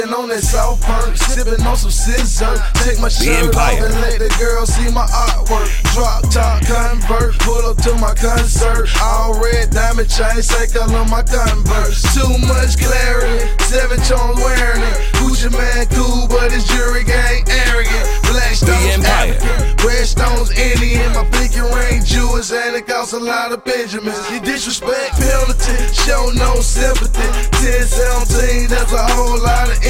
Inicio » Ringtones para celulares » Hiphop